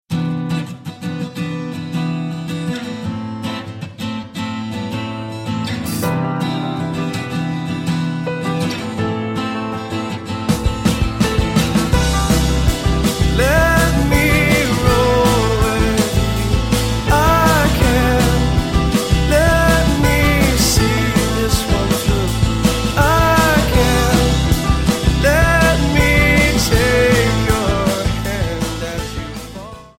5 piece Indie Rock Band
Alternative,Rock